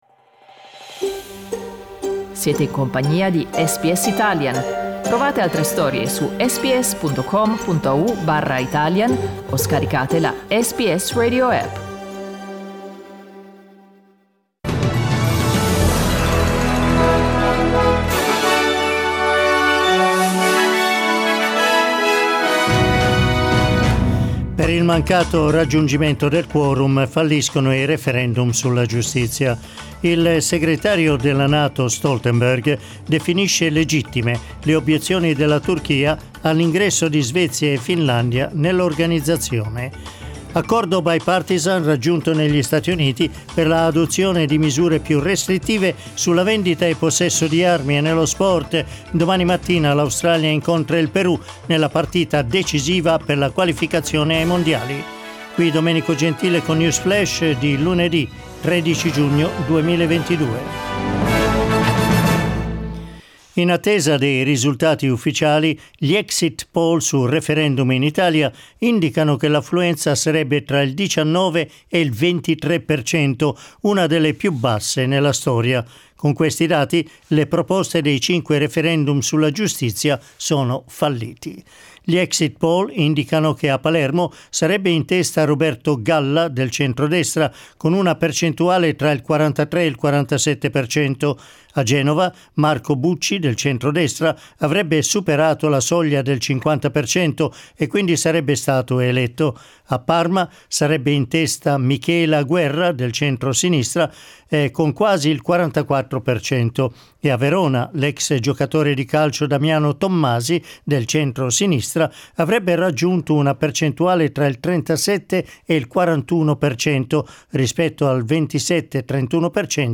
L'aggiornamento delle notizie di SBS Italian.